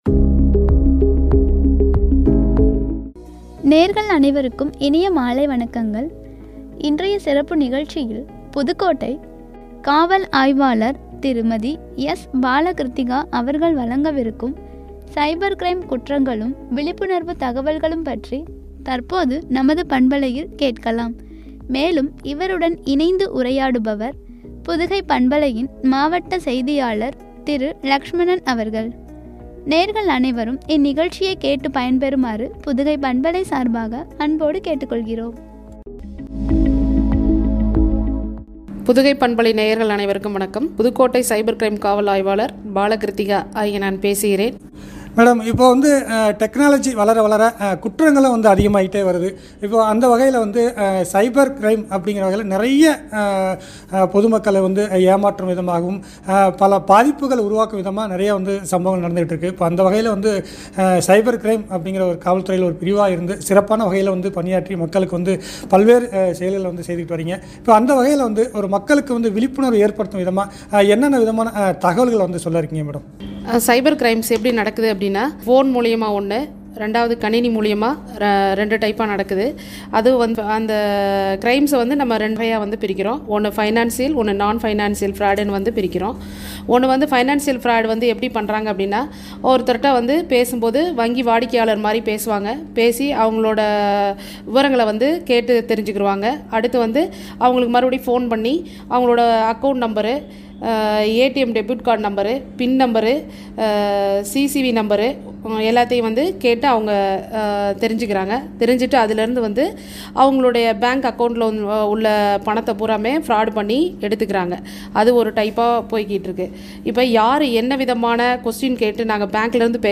உரையாடல்